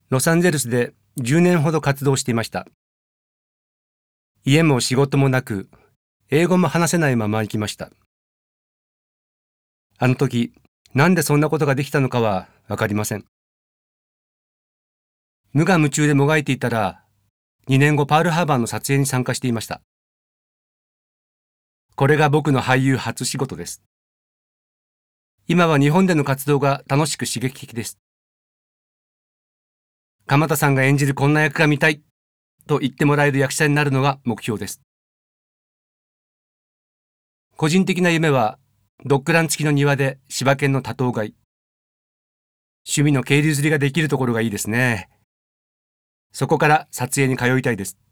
ボイスサンプル、その他